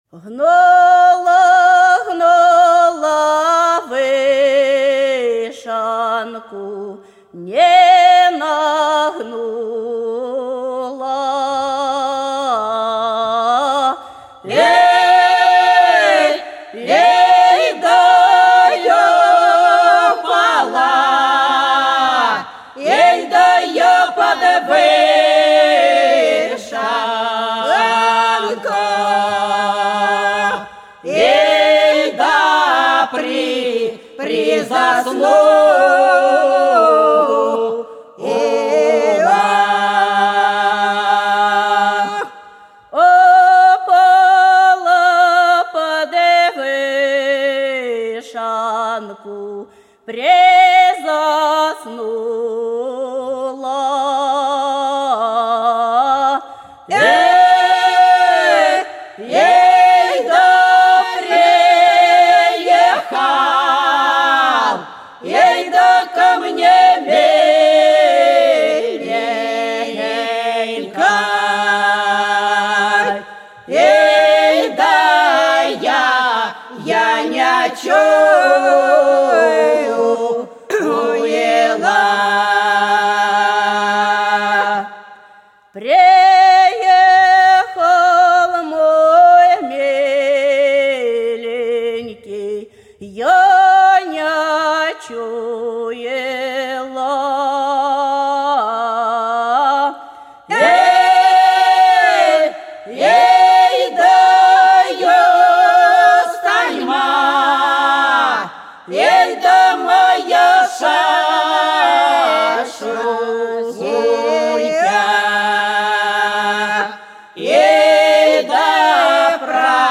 За речкою диво Гнула, гнула вишенку - протяжная (с. Гвазда)